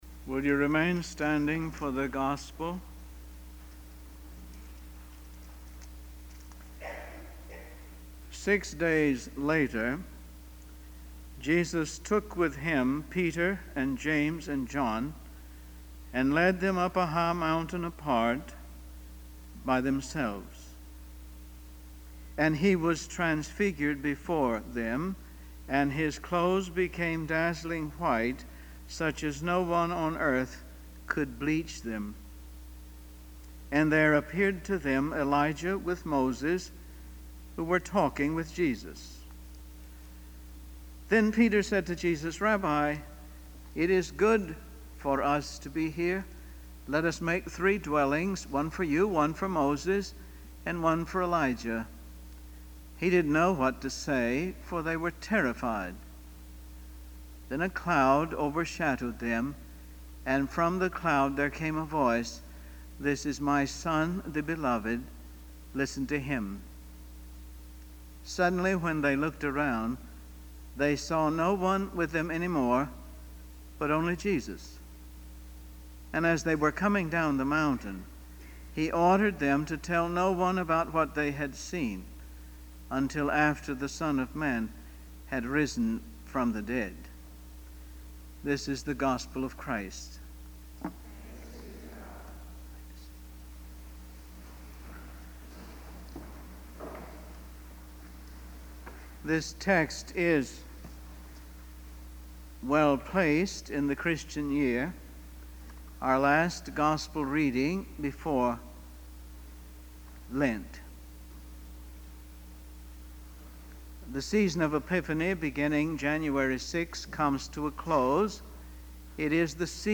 Emory University Worship Service